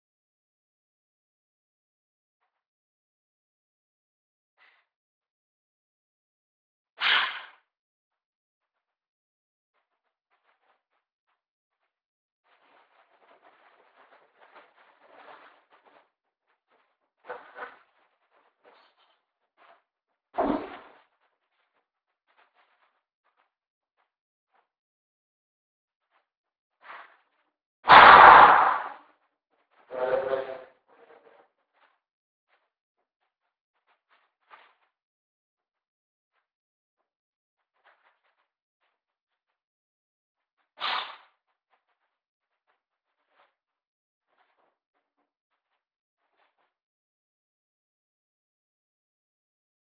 The room has wooden floorboards and any footsteps approaching the voice recorder would have been captured also. There were no footsteps.
Cold War Room2.wav